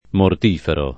[ mort & fero ]